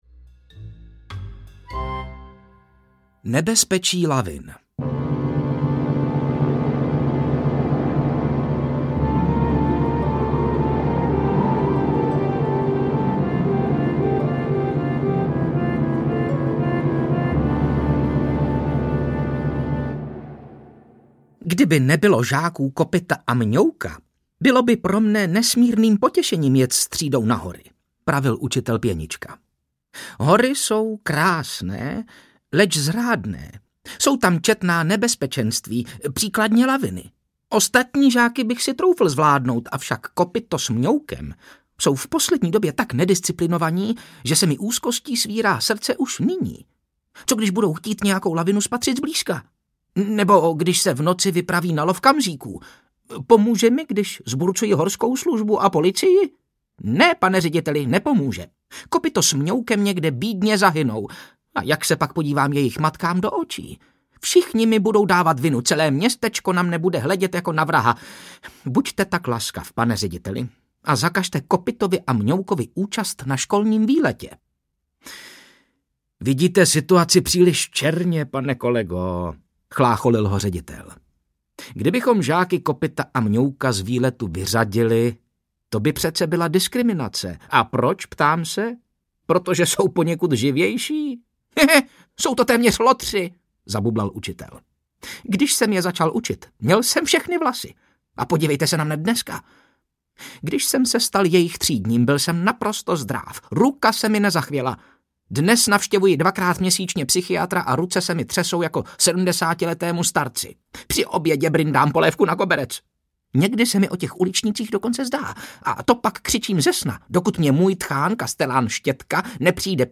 Žáci Kopyto a Mňouk audiokniha
Ukázka z knihy